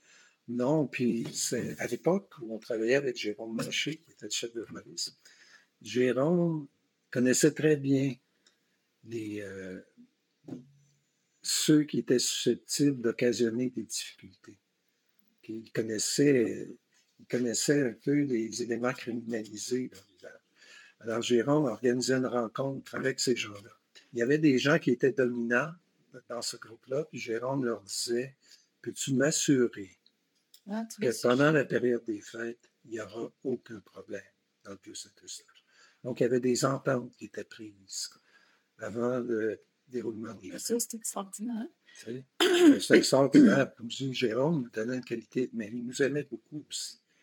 Audio excerpt: Interview